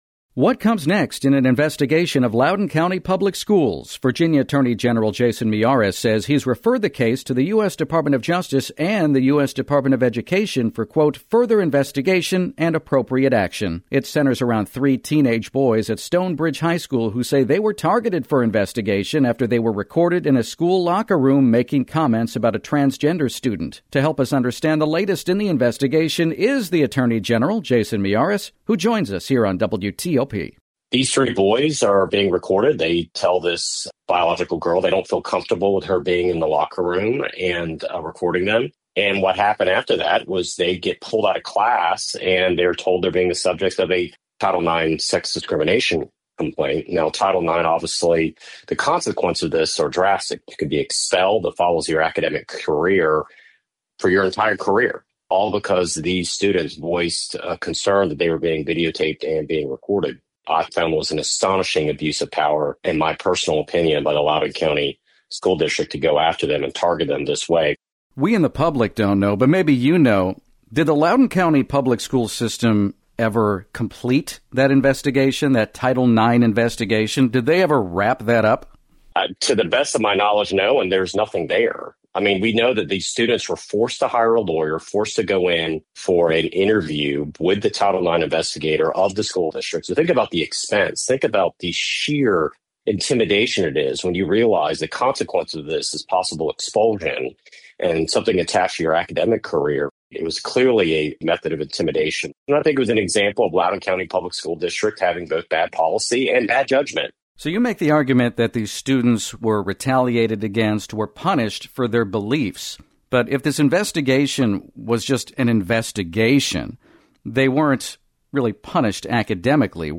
Miyares-Interview.mp3